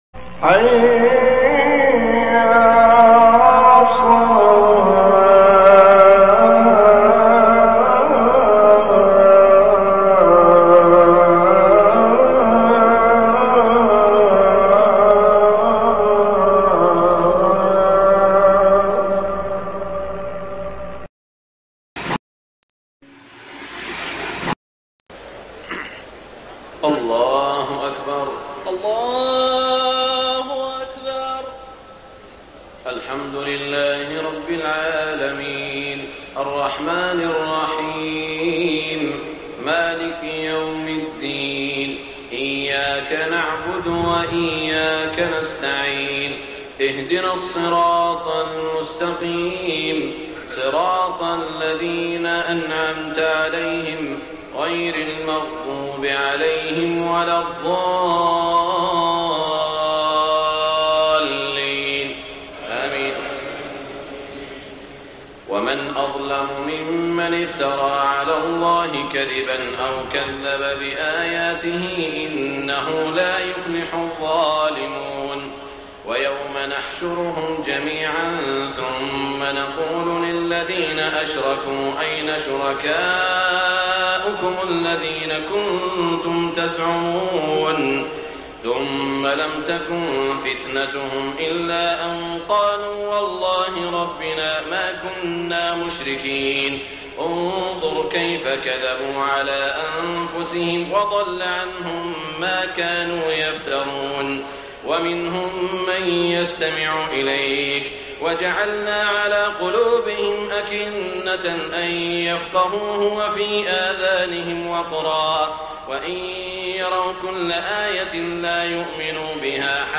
صلاة التهجد رمضان عام 1424هـ من سورة الأنعام > تراويح الحرم المكي عام 1424 🕋 > التراويح - تلاوات الحرمين